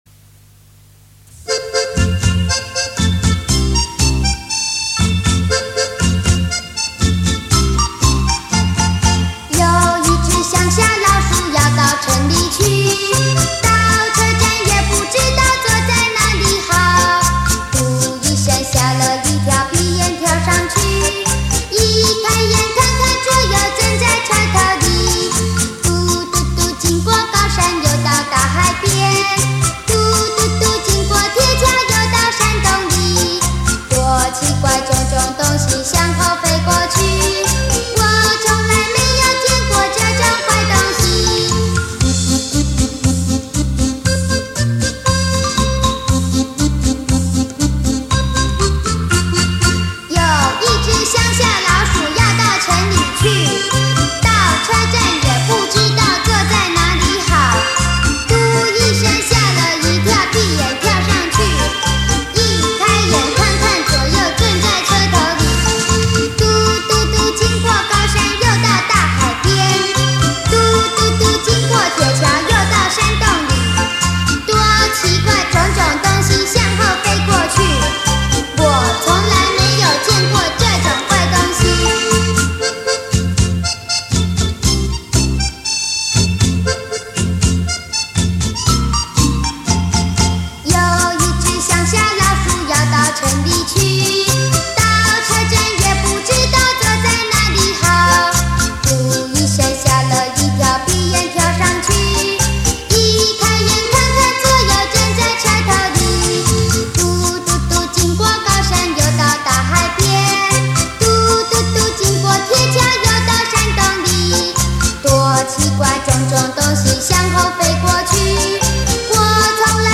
[14/10/2008]乡下老鼠——童谣 激动社区，陪你一起慢慢变老！